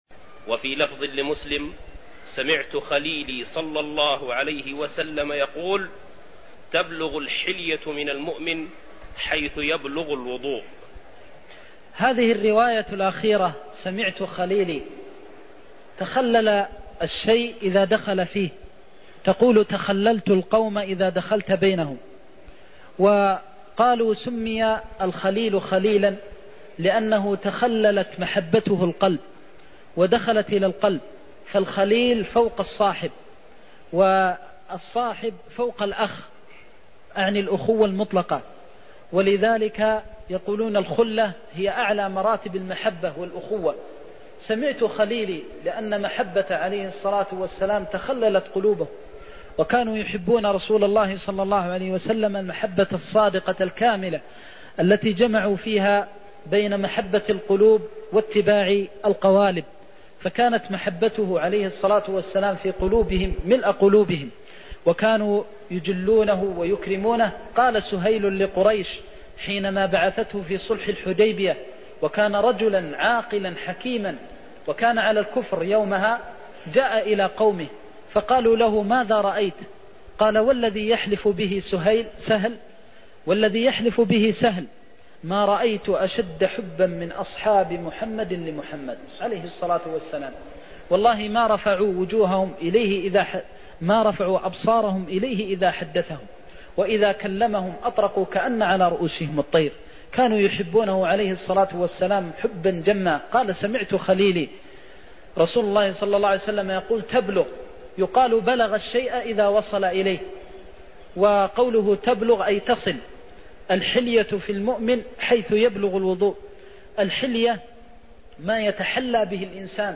المكان: المسجد النبوي الشيخ: فضيلة الشيخ د. محمد بن محمد المختار فضيلة الشيخ د. محمد بن محمد المختار تبلغ الحلية من المؤمن حيث يبلغ الوضوء (11)